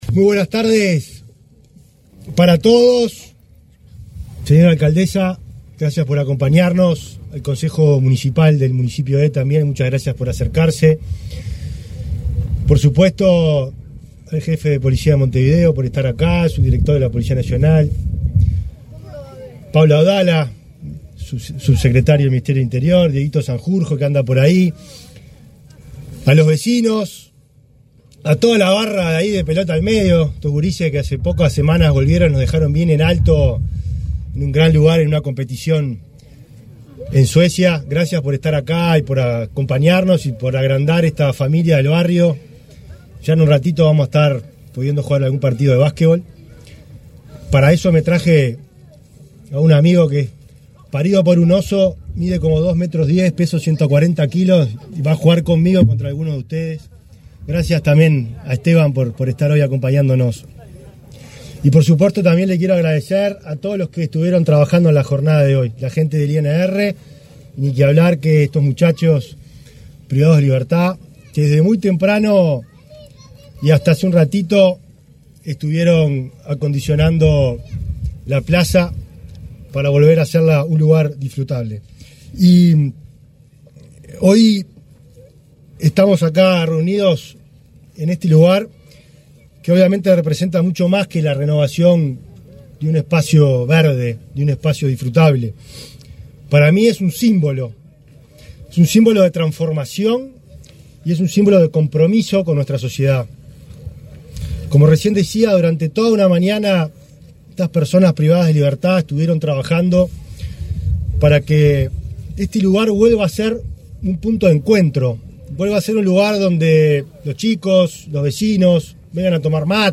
Palabras del ministro del Interior, Nicolás Martinelli
El ministro del Interior, Nicolás Martinelli, participó, este 16 de agosto, en la presentación de las obras de recuperación de una plaza en el barrio